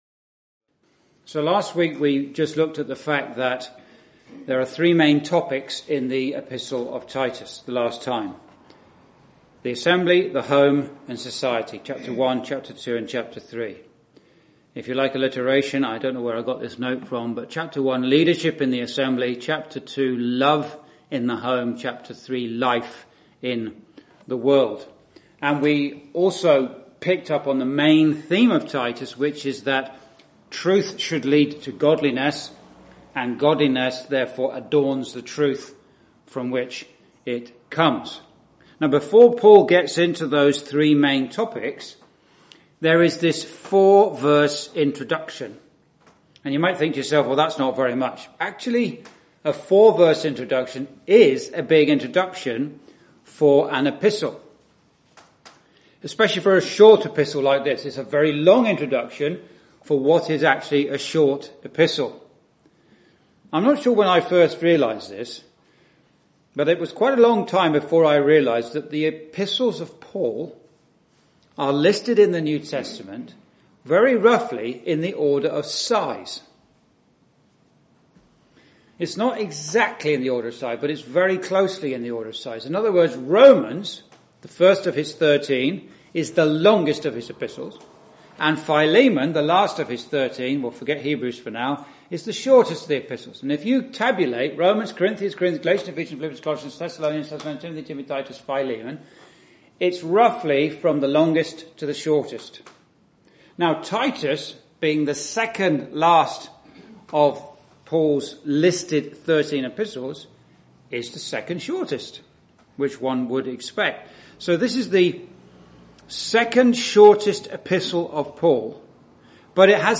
(Message preached in Chalfont St Peter Gospel Hall, 2022)